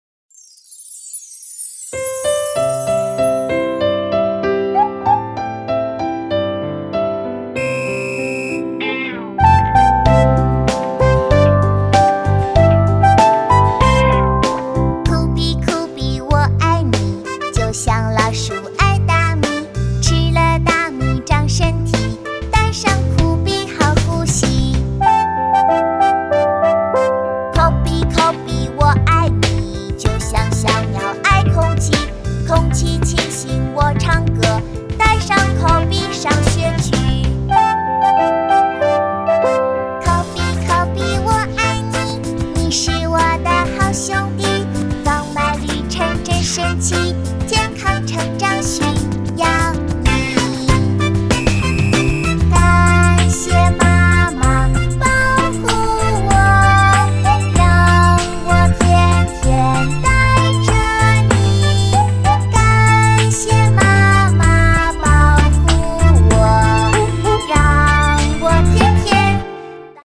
女声